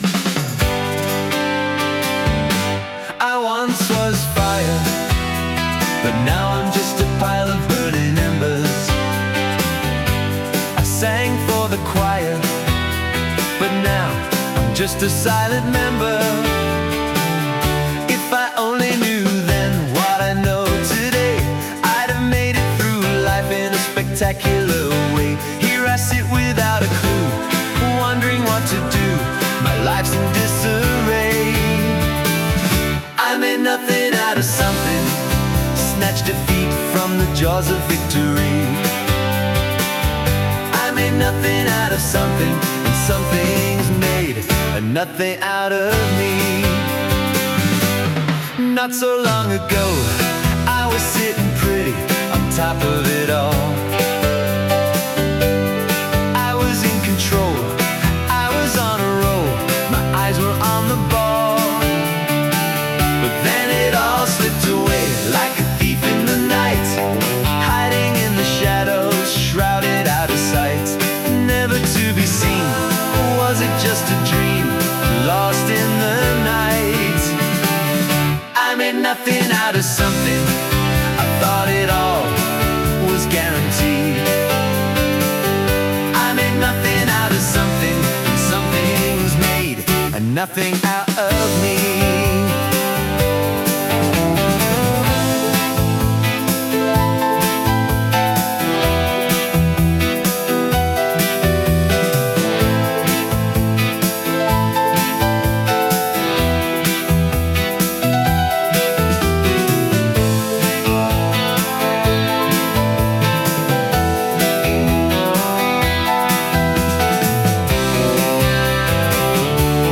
Then, just for fun, I ran it into Suno with a 60’s British Pop prompt; which makes it much more cheery.
Nothing-Out-of-Something-60s-Brit-Pop.mp3